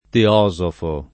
teosofo [ te 0@ ofo ] s. m.